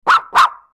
Dog Bark Type 08 Sound Button - Free Download & Play